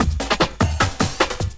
50 LOOP10 -L.wav